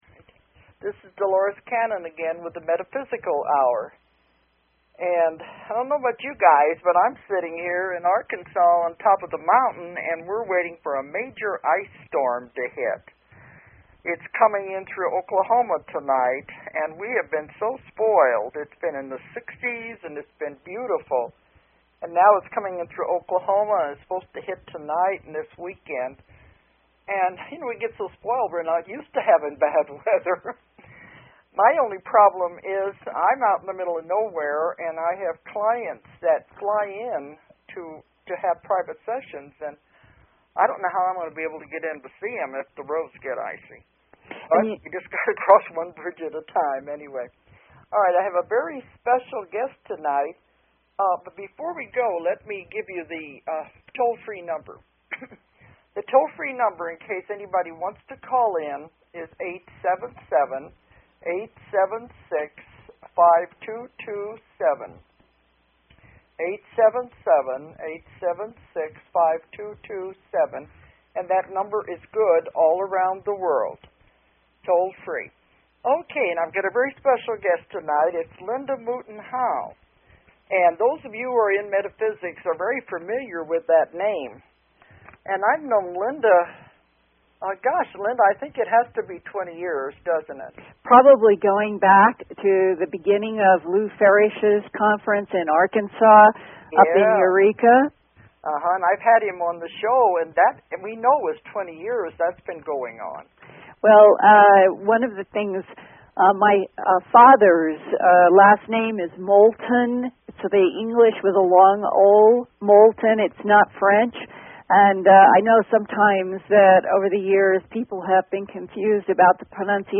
Special Guest Linda Moulton Howe